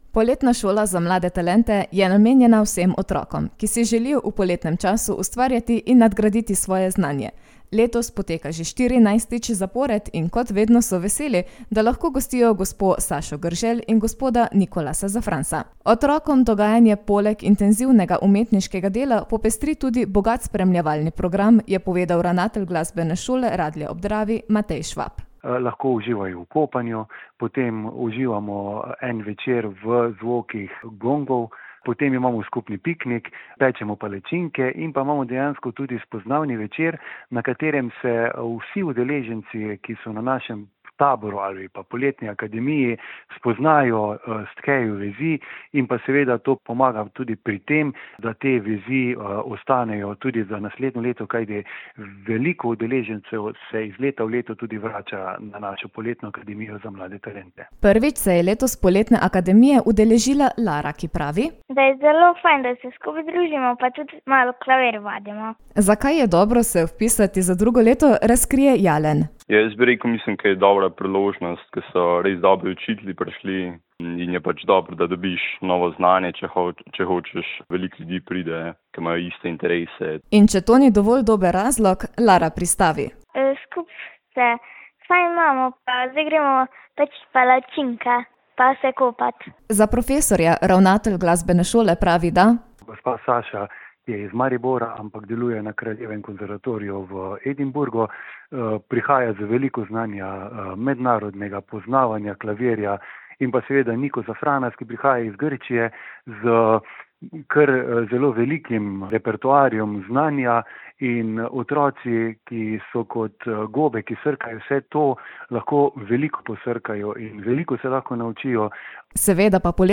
Obiskali smo jih z radijskim mikrofonom.